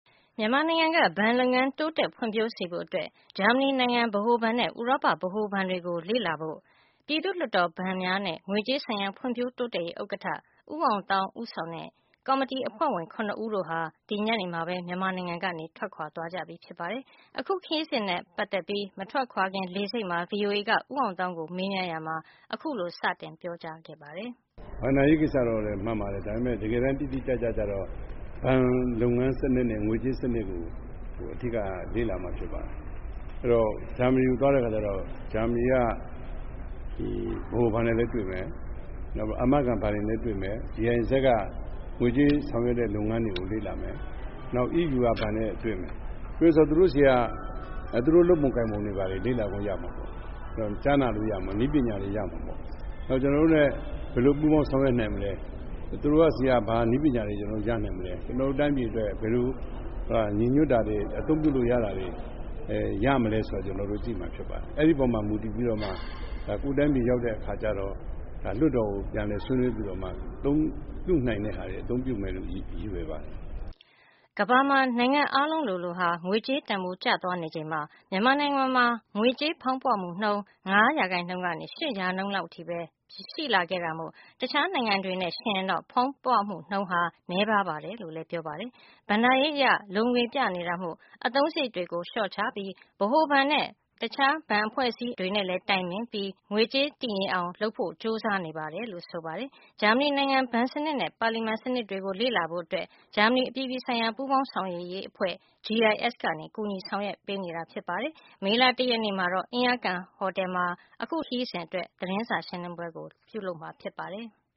U Aung Thaung Presser